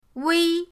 wei1.mp3